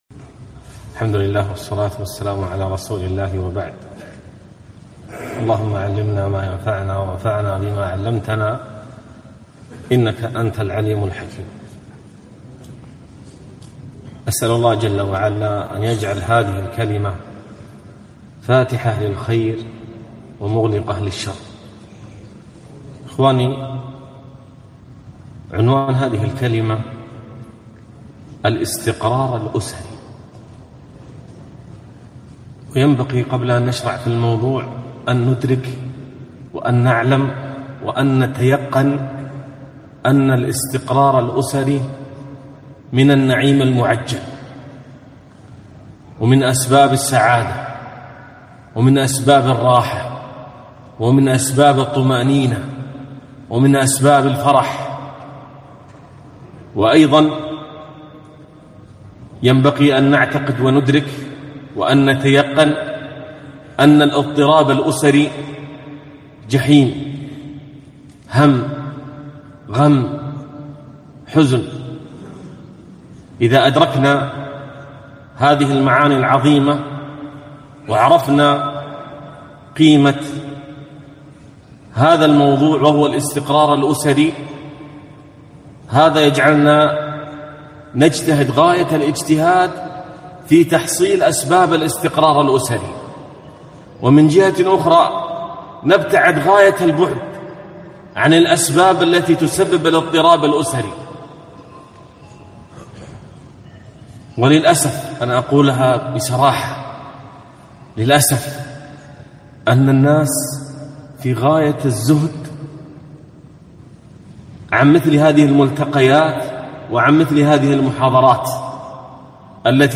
محاضرة قيمة - استقرار الأسرة